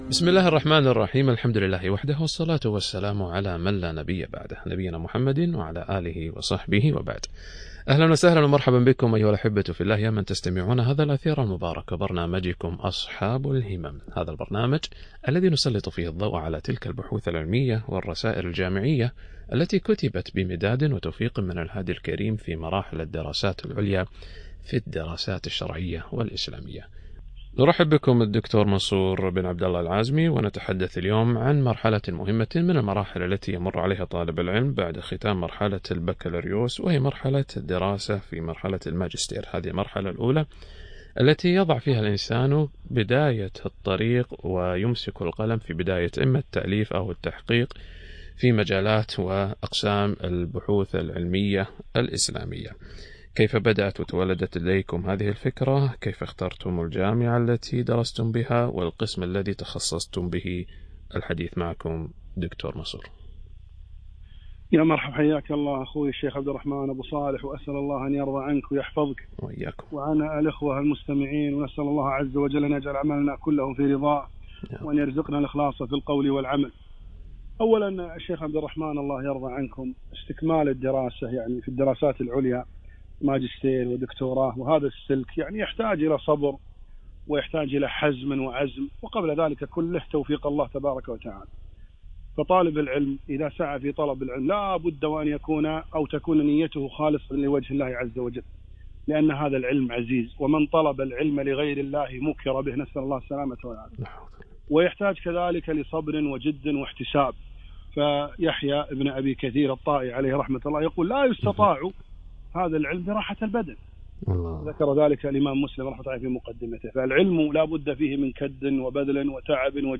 لقاء إذاعي في برنامج أصحاب الهمم عبر إذاعة القرآن الكريم